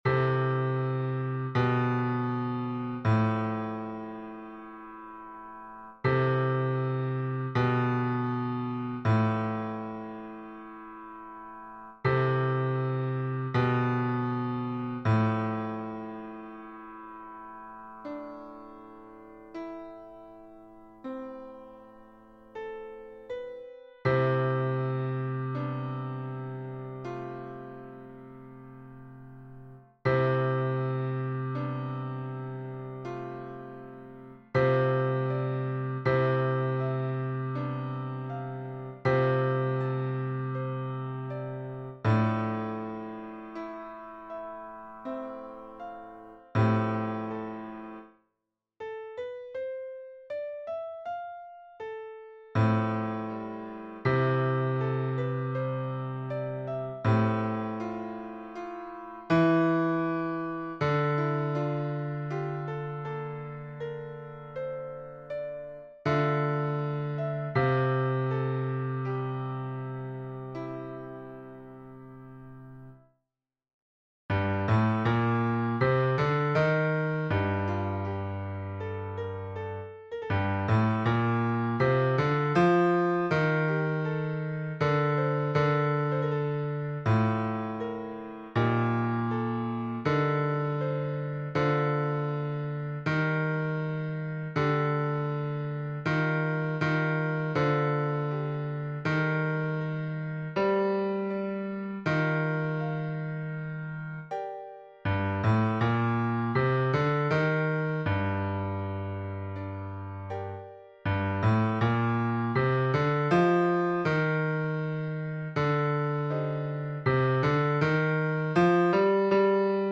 Basse (version piano)